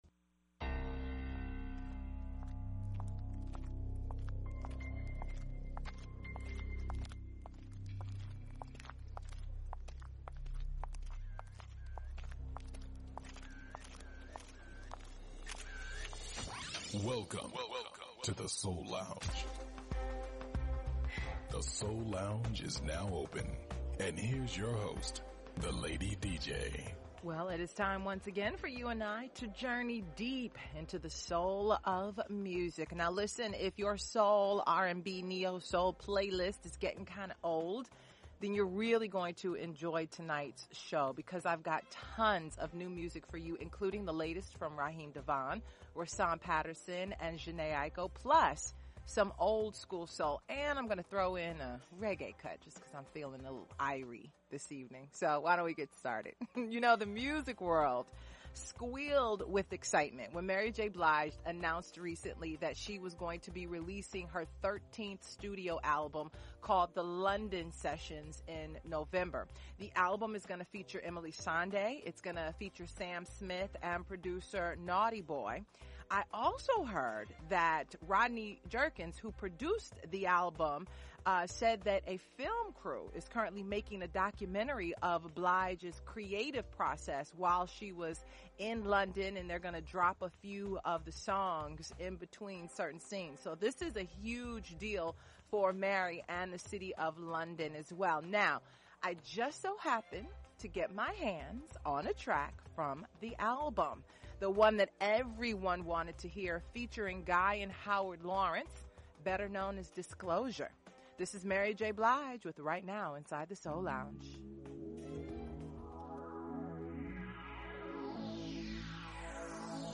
Neo-Soul
conscious Hip-Hop
Classic Soul